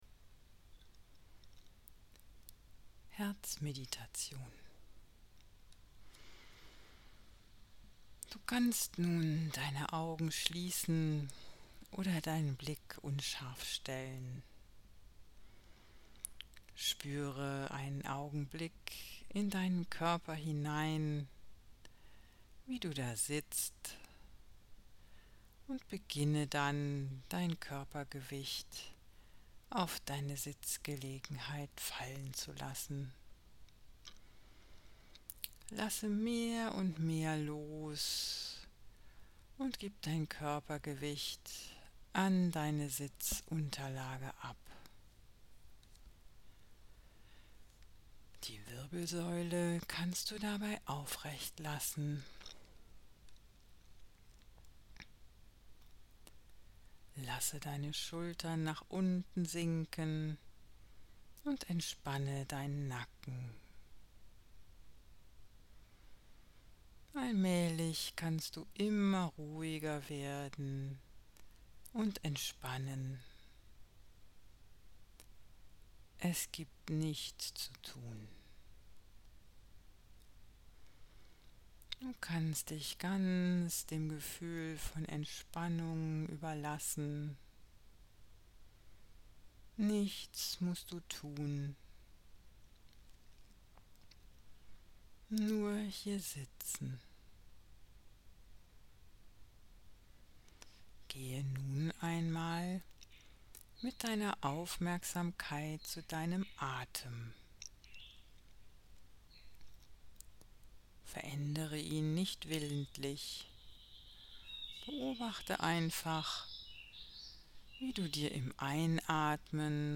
Herzmeditation_1-13min.mp3